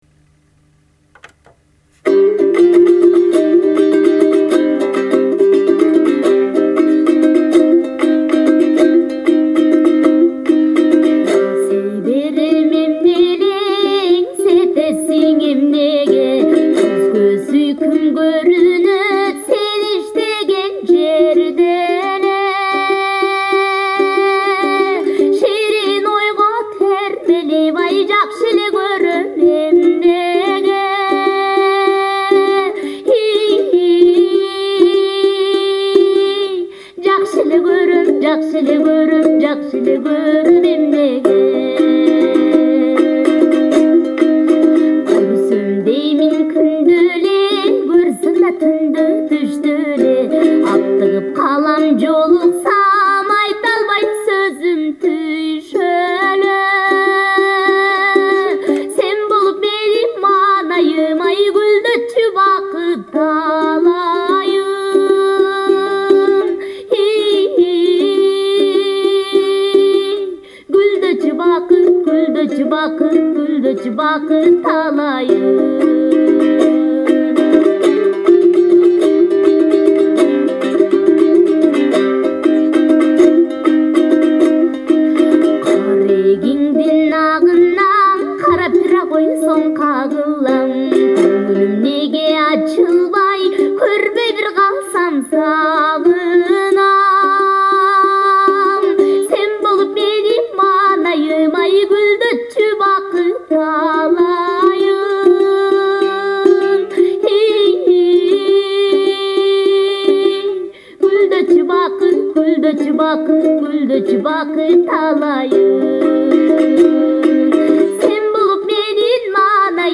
キルギスの民族音楽・女声　（ＣＤ）
kyrgyz-female.MP3